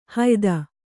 ♪ hayda